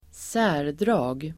Uttal: [²s'ä:r_dra:g]